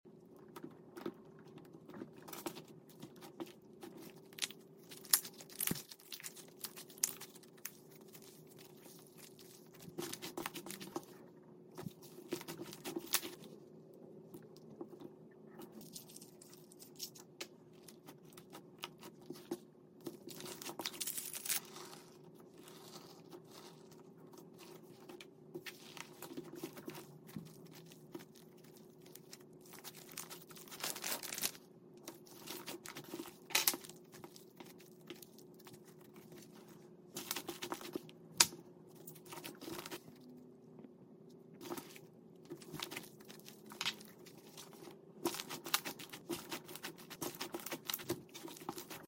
Adding Clay And Emoji Charms Sound Effects Free Download